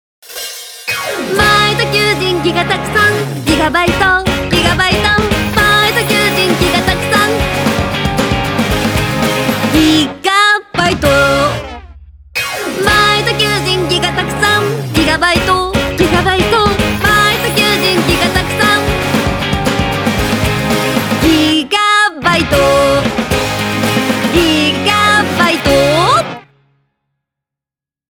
ロックでポップなサウンドを作りました。
TVCM
SONG ROCK / POPS